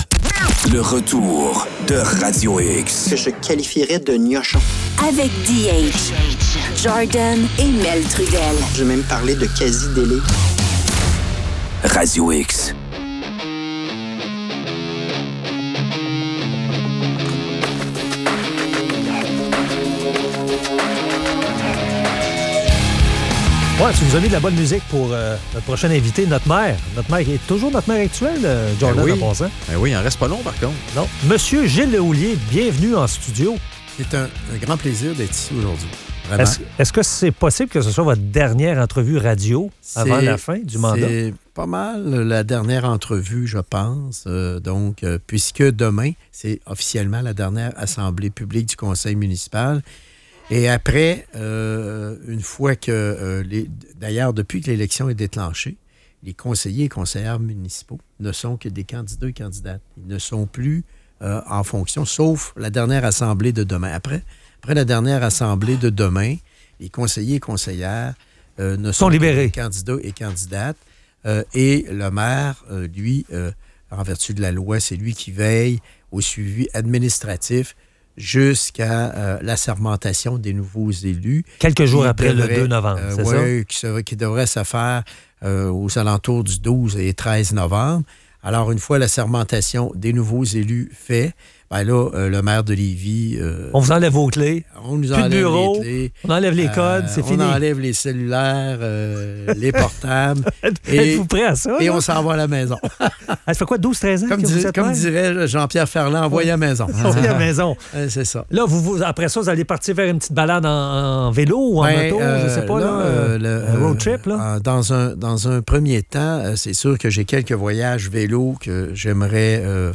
Entrevue avec le maire sortant de Lévis, Gilles Lehouillier.